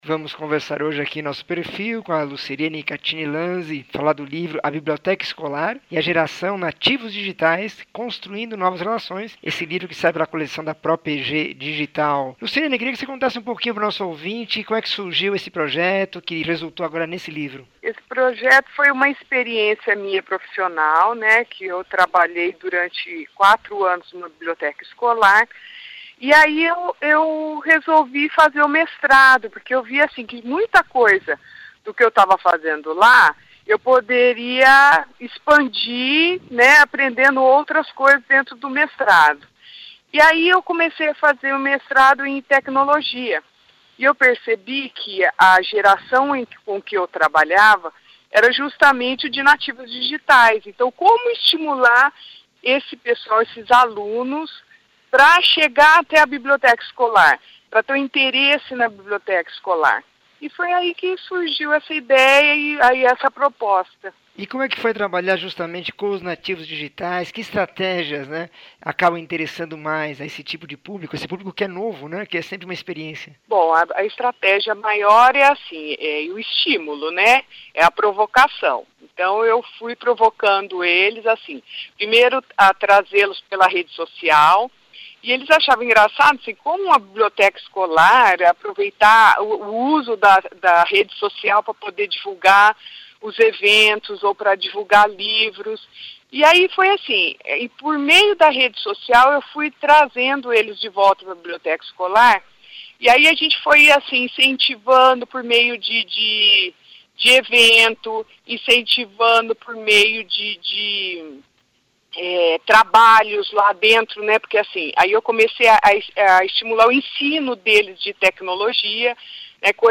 entrevista 1995